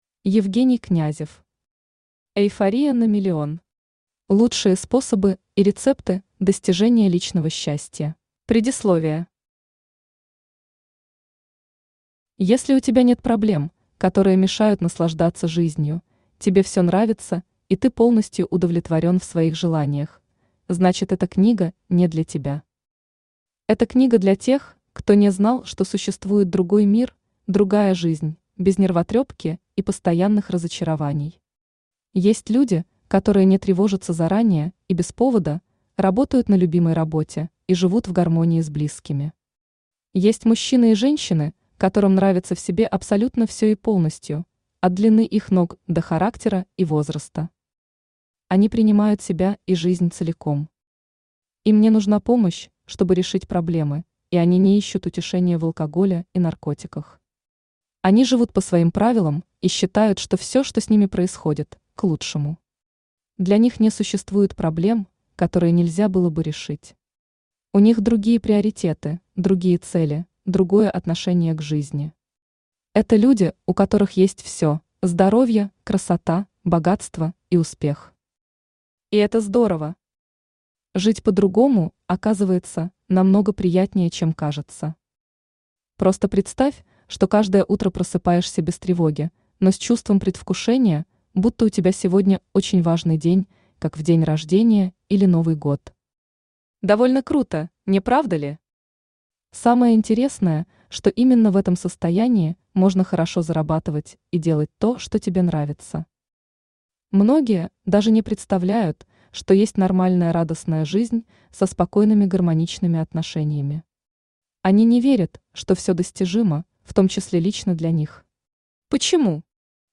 Аудиокнига Эйфория на миллион. Лучшие способы и рецепты достижения личного счастья.
Автор Евгений Князев Читает аудиокнигу Авточтец ЛитРес.